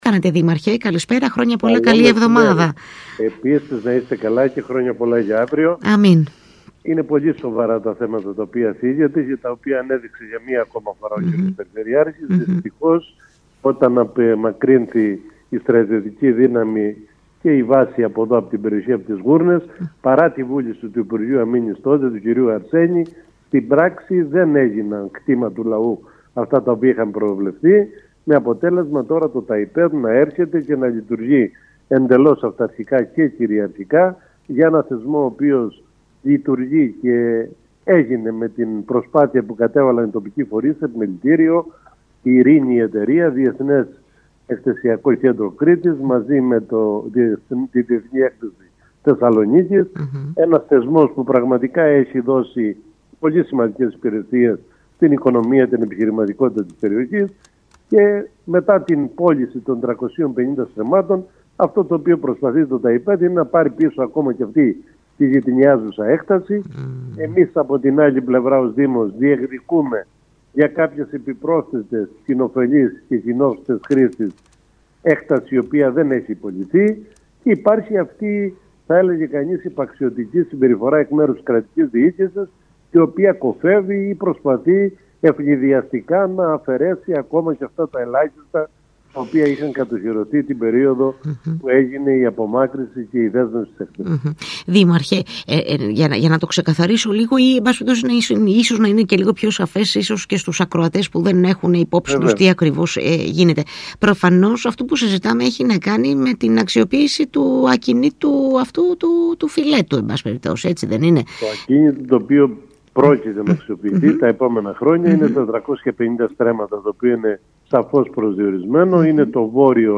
Μιλώντας στον ΣΚΑΪ Κρήτης 92.1